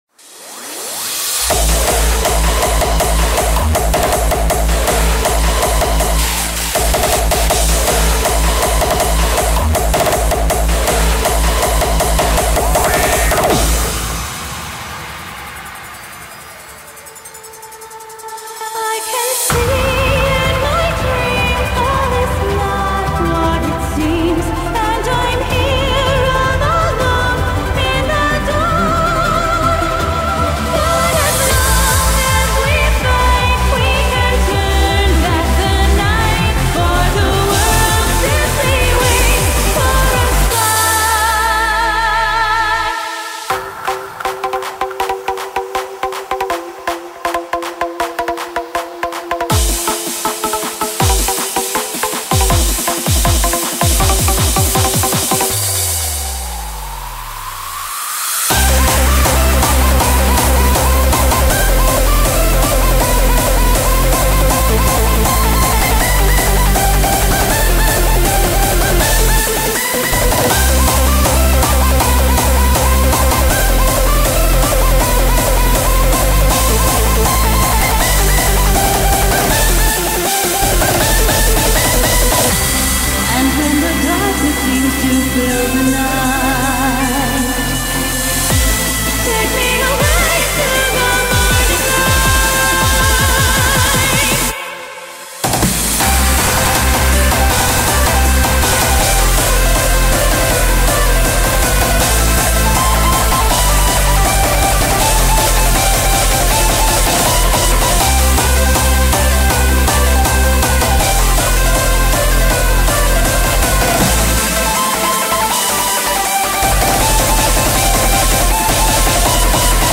BPM160
Audio QualityPerfect (High Quality)
Comments[EUPHORIC HARDSTYLE]